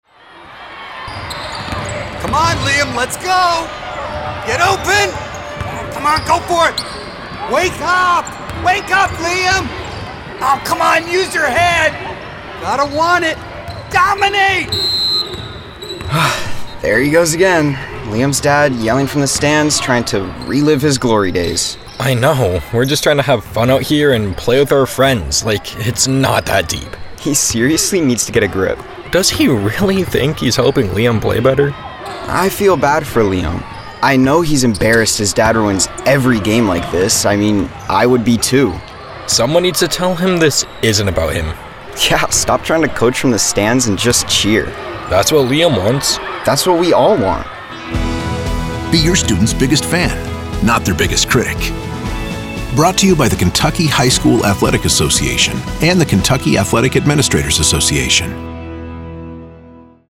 25-26 Radio – Public Service Announcements